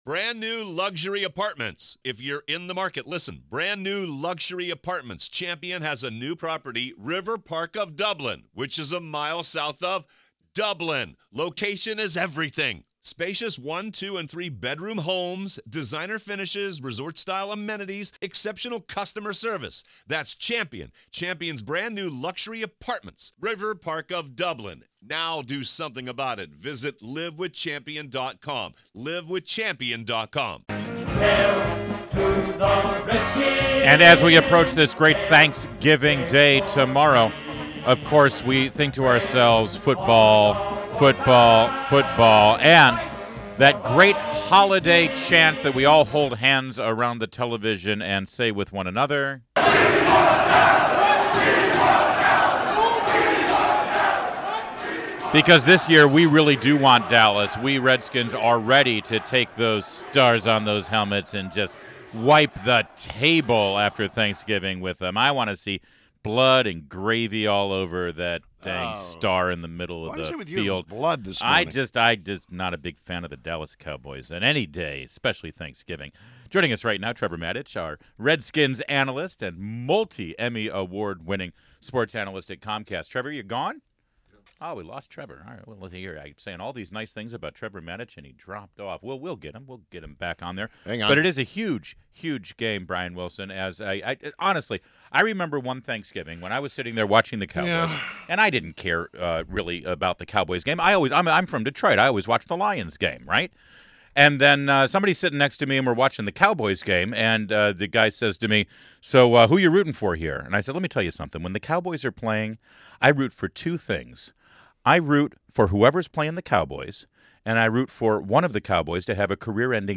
WMAL Interview - TREVOR MATICH - 11.23.16